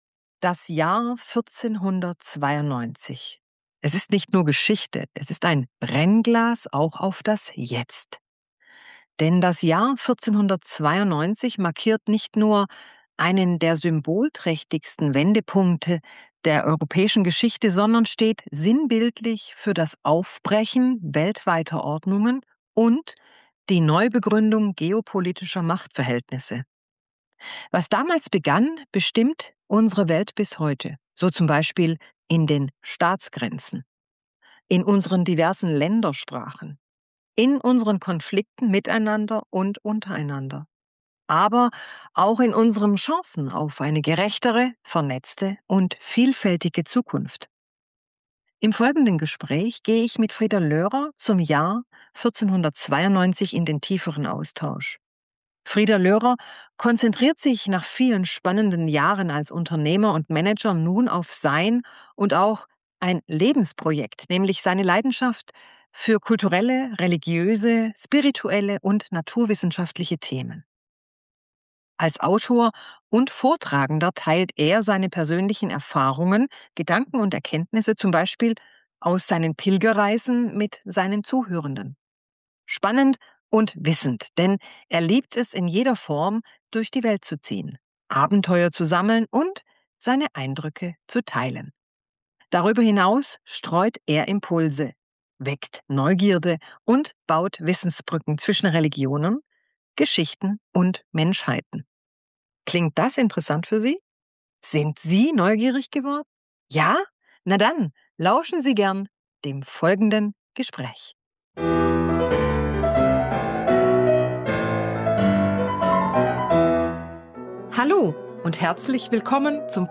Dann lauschen Sie gerne dem folgenden Gespräch …